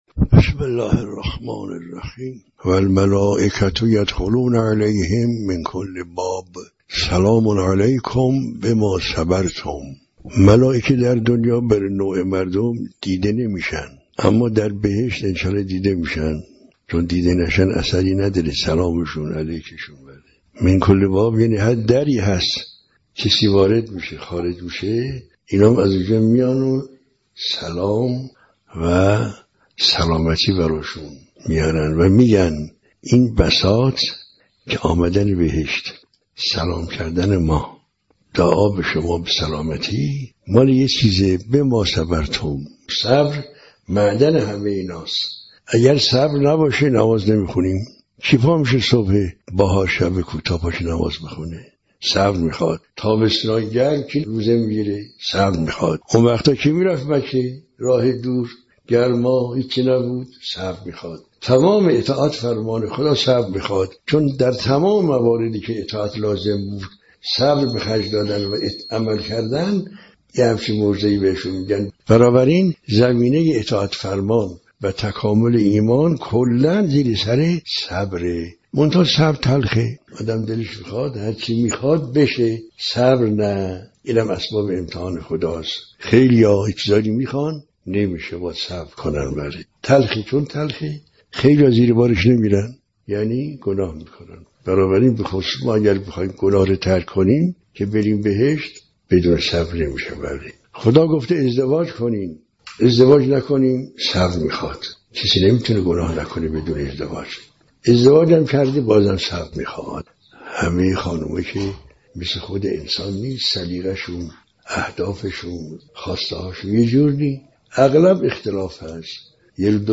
در یکی از دروس اخلاق خود